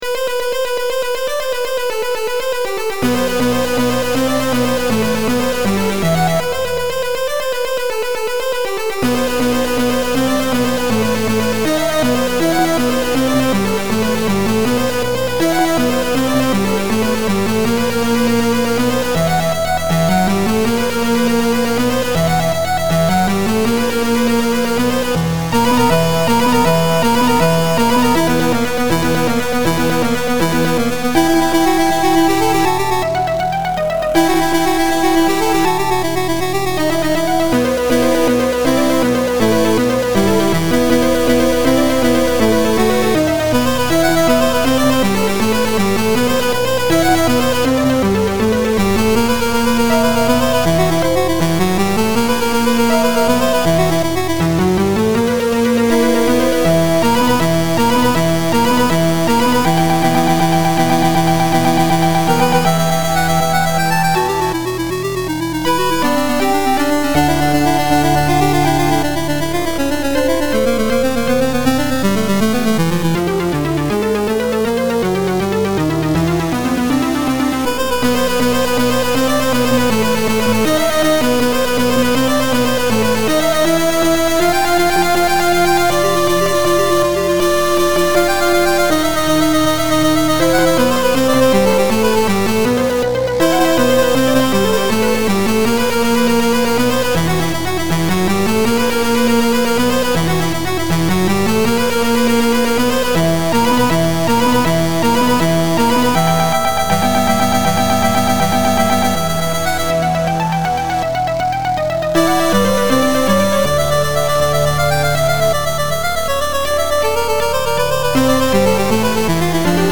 chpitune-style rendition of Chorus of Dervishes by Beethoven (very ominous)